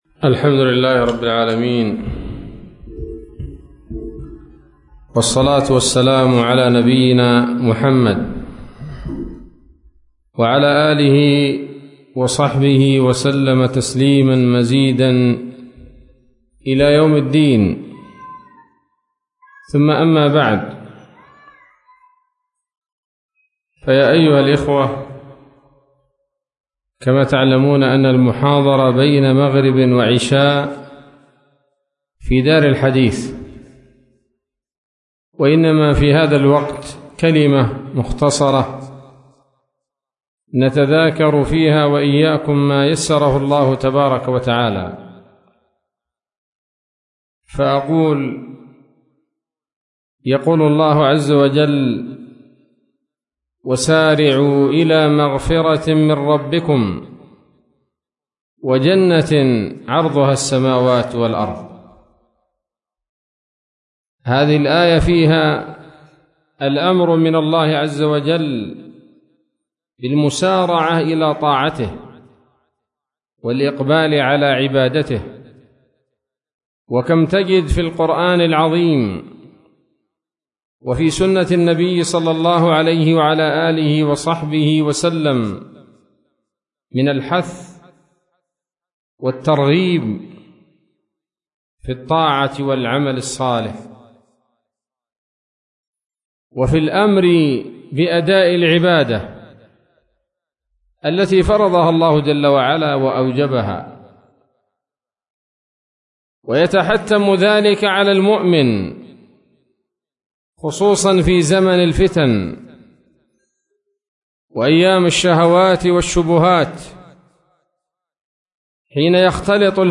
كلمة قيمة بعنوان: (( ‌مسابقة الفتن بطاعة الله )) عصر الثلاثاء 20 ذو الحجة 1443هـ، بمسجد القرين بالعطف - وادي حطيب - يافع